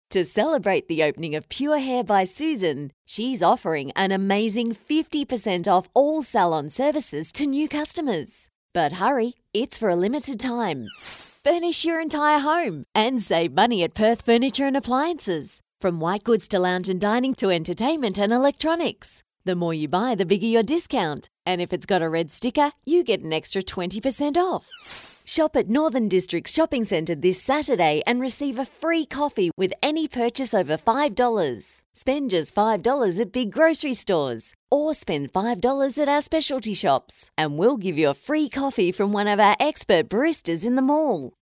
I’m an authentic Australian female voiceover artist with over 18 years of experience.
I can provide a quality studio recording from my home studio with a Rode NT1-A microphone and Adobe Audition editing software.
• Hard Sell